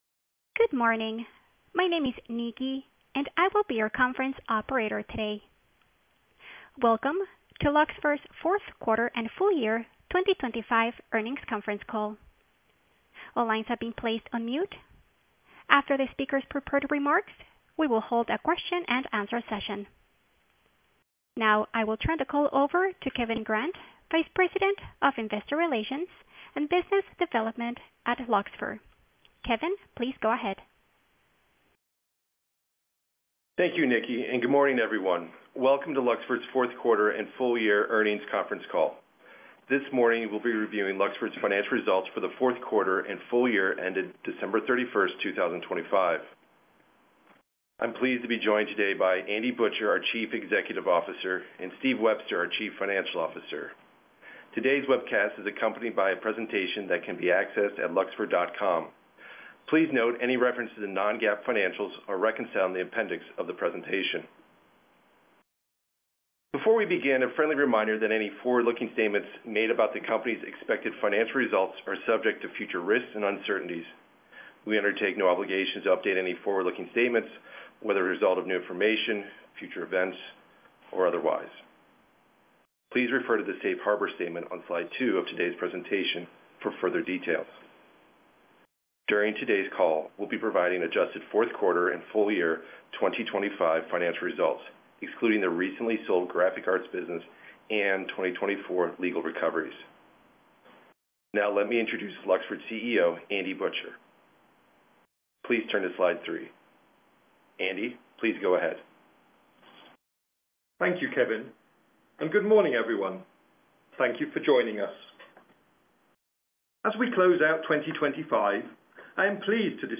2023 Q4 earnings Call Audio Replay (Opens in a new browser window)